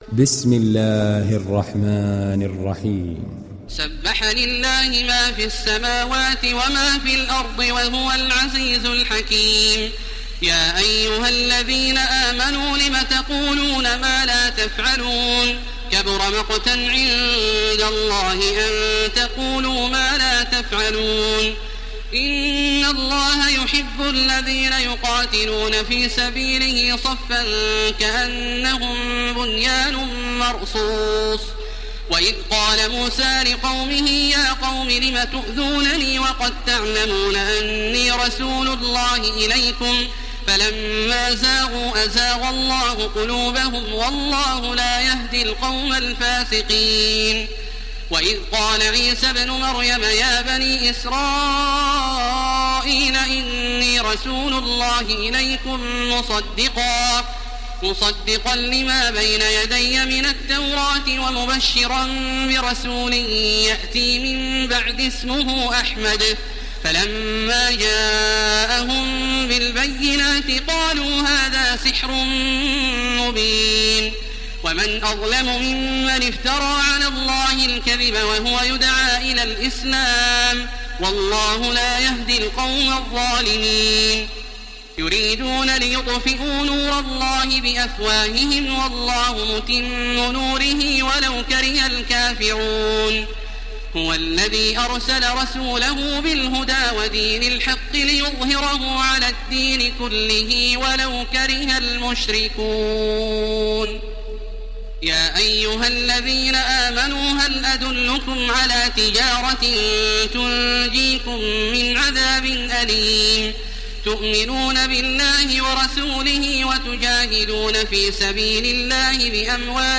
Sourate As Saf Télécharger mp3 Taraweeh Makkah 1430 Riwayat Hafs an Assim, Téléchargez le Coran et écoutez les liens directs complets mp3
Télécharger Sourate As Saf Taraweeh Makkah 1430